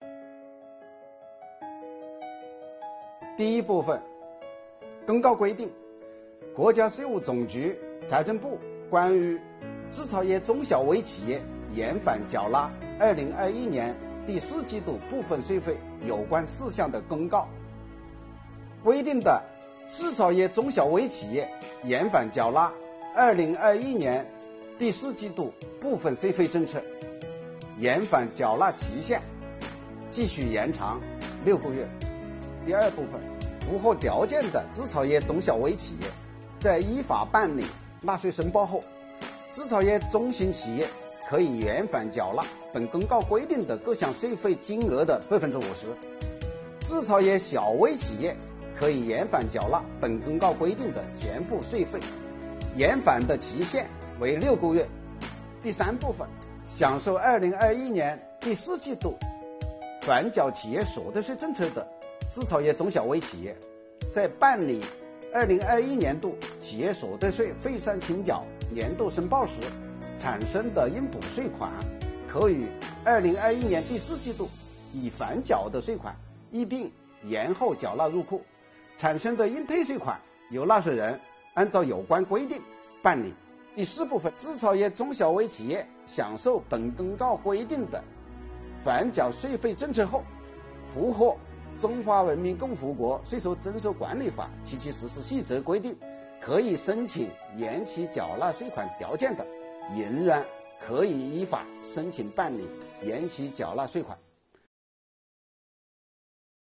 本期课程国家税务总局征管和科技发展司副司长付扬帆担任主讲人，对制造业中小微企业缓缴税费政策解读进行详细讲解，确保大家能够及时、便利地享受政策红利。